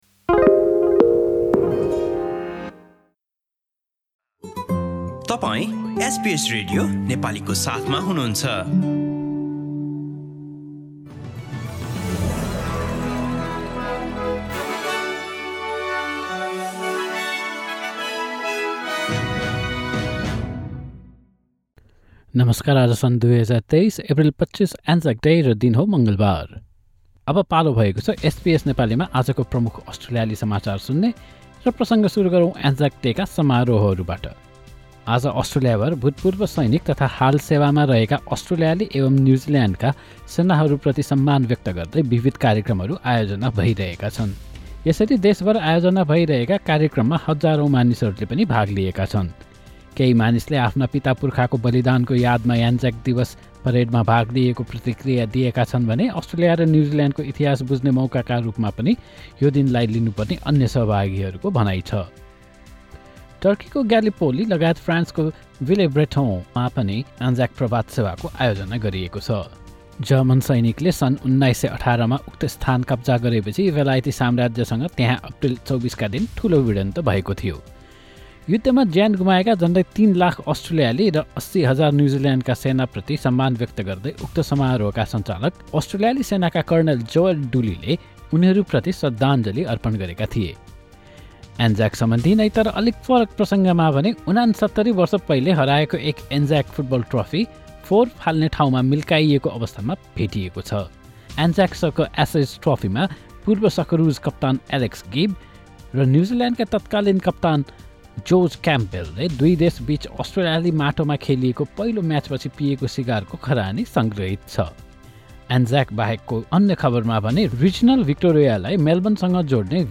एसबीएस नेपाली प्रमुख अस्ट्रेलियाली समाचार : मङ्गलवार, २५ एप्रिल २०२३